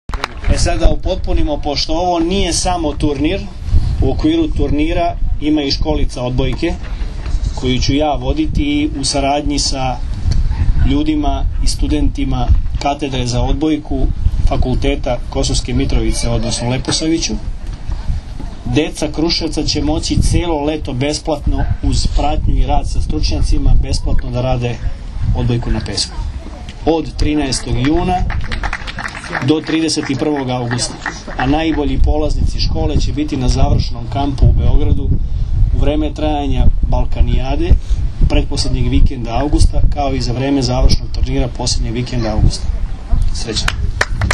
IZJAVA VLADIMIRA GRBIĆA 2